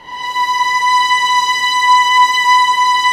Index of /90_sSampleCDs/Roland L-CD702/VOL-1/STR_Vlns 6 mf-f/STR_Vls6 mf amb